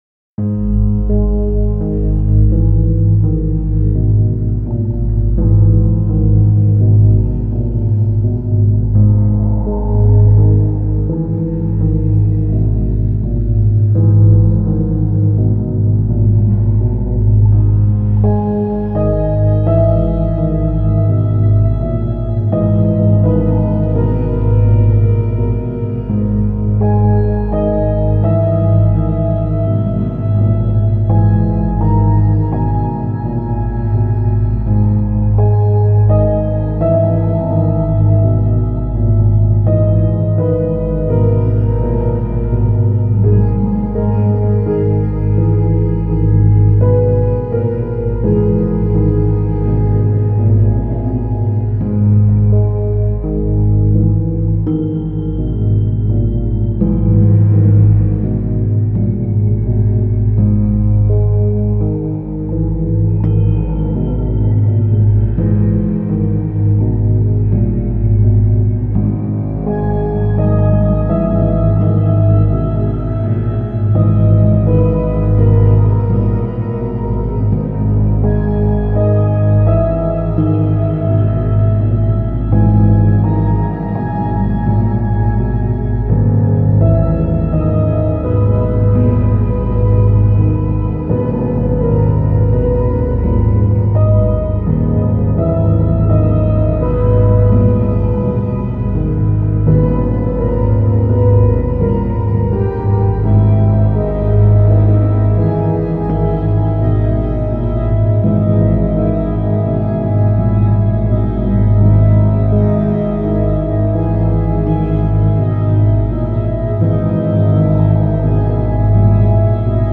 水の効果音を使用した静かでミステリアスな曲です。
ピアノ,エレピ,木琴,シンセパッド etc